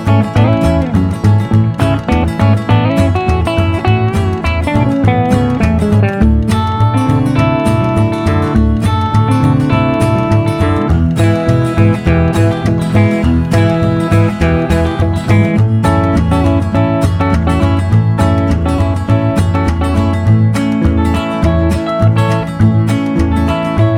No Male Harmony Pop